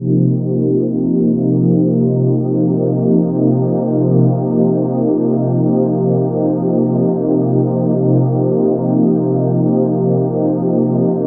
Index of /90_sSampleCDs/USB Soundscan vol.13 - Ethereal Atmosphere [AKAI] 1CD/Partition A/01-AMBIANT A